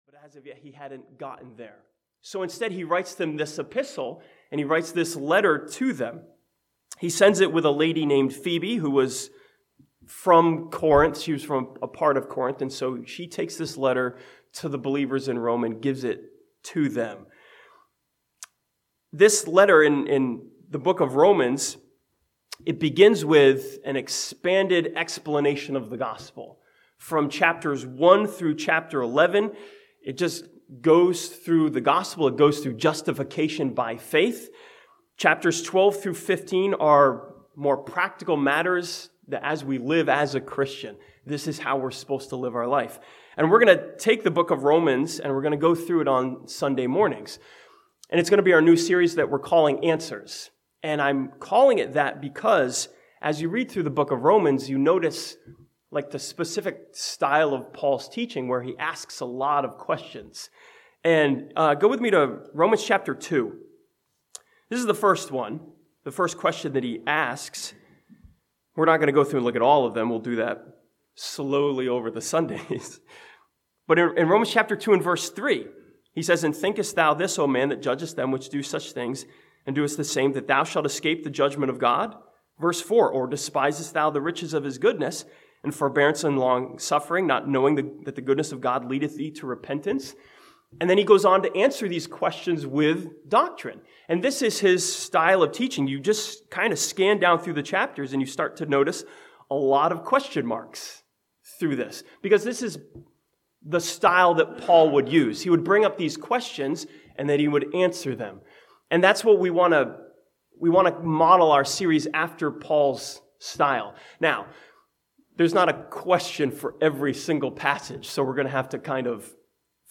This sermon from Romans chapter 1 begins a series titled "Answers" and answers the question "Who am I?" from Romans 1:7.